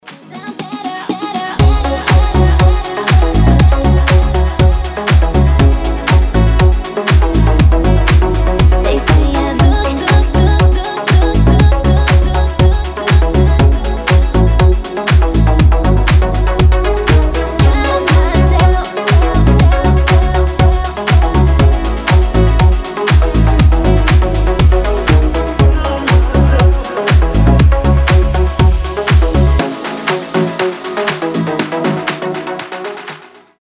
deep house
nu disco